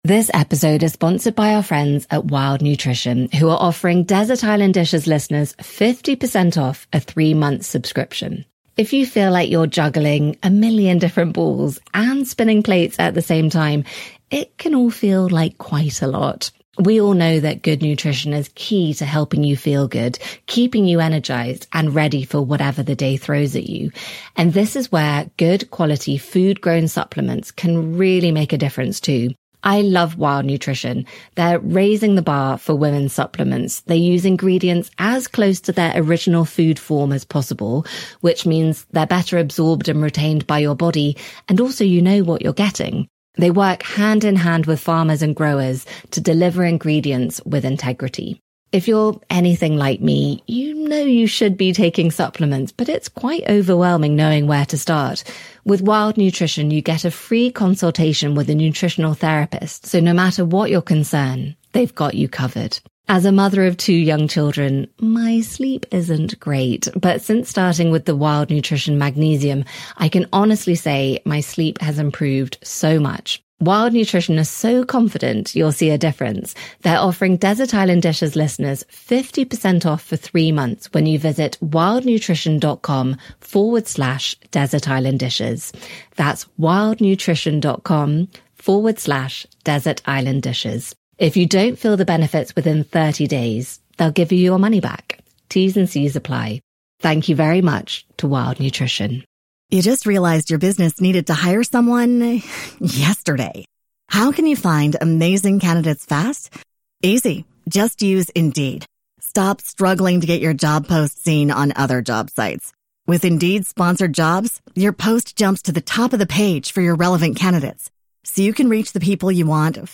My guest today is Anna Jones